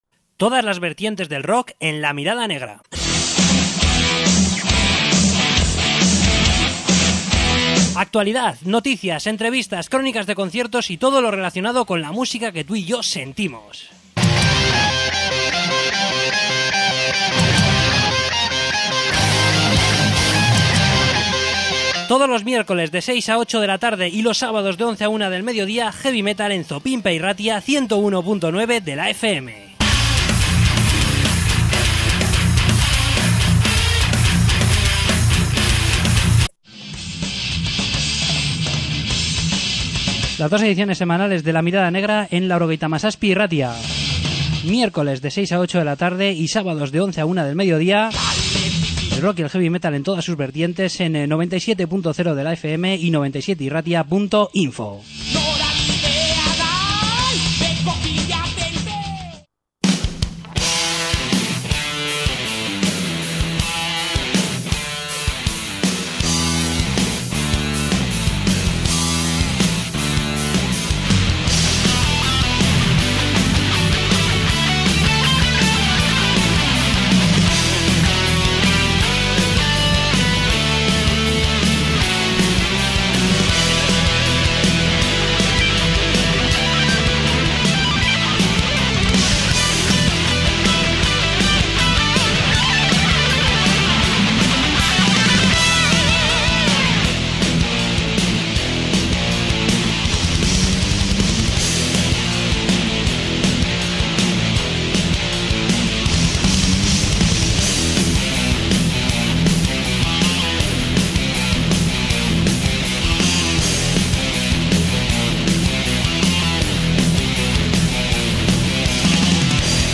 Entrevista con Tierra Santa